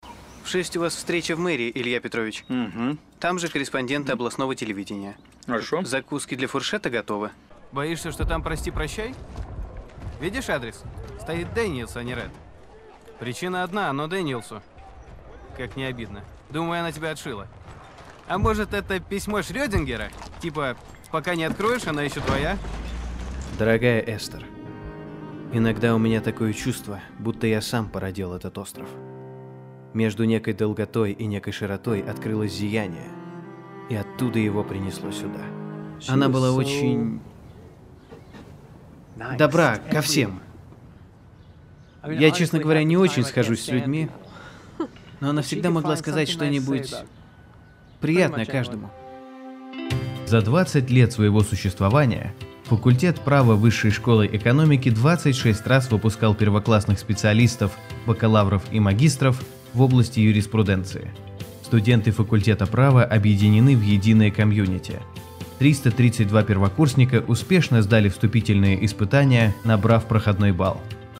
Микрофон AKG P220, звуковая карта Focusrite scarlett solo, акустическая кабина